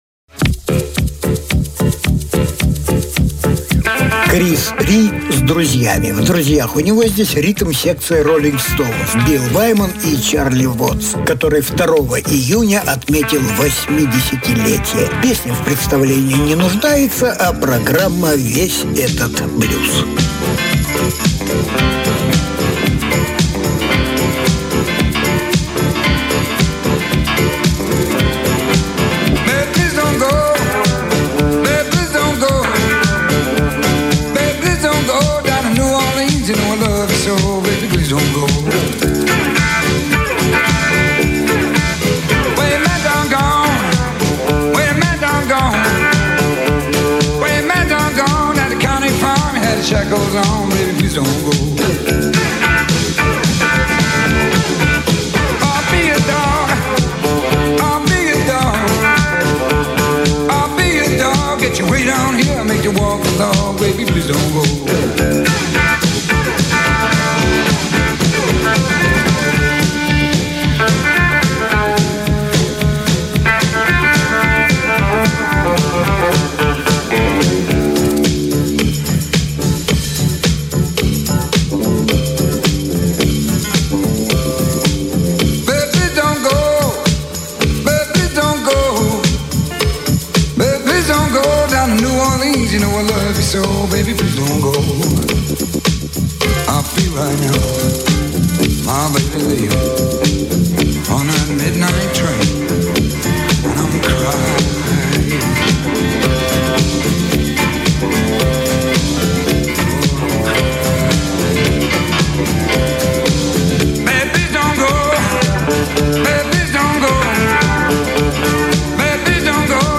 ударник
певец и харпер